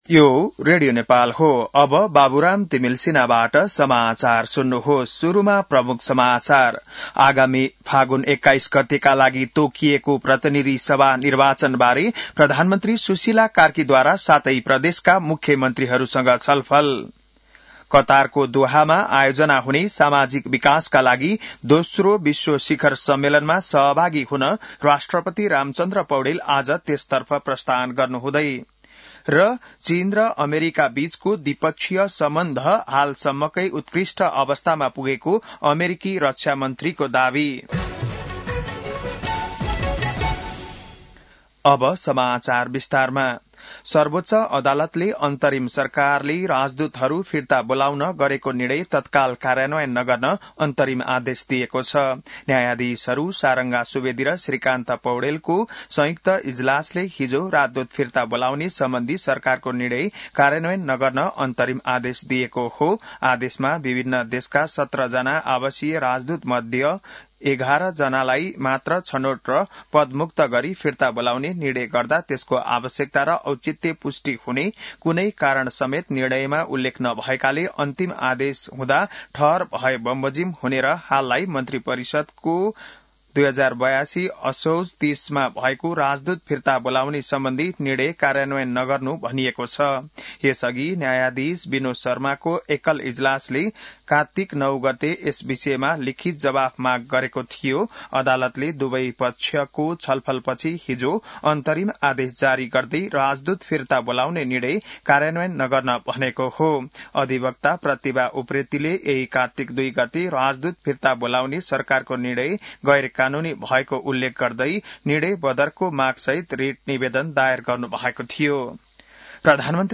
बिहान ९ बजेको नेपाली समाचार : १७ कार्तिक , २०८२